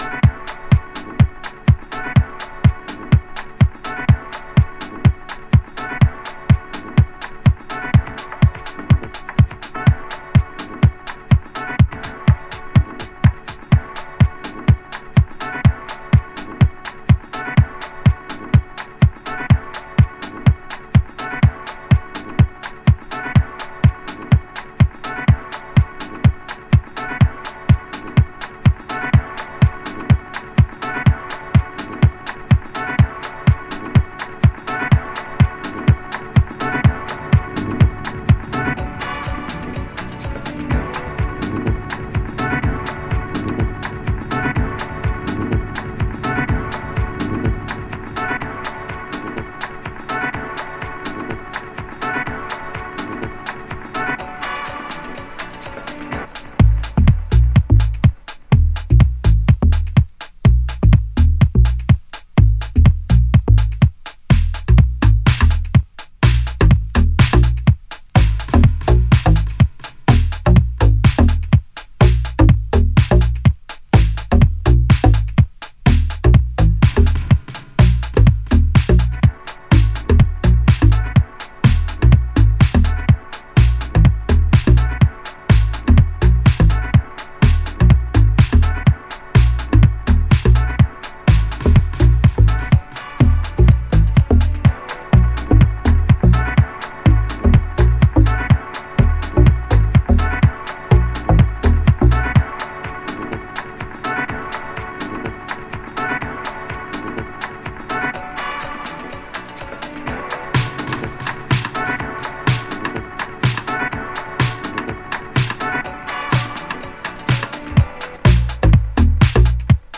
愛すべき四畳半マシーンファンク。
House / Techno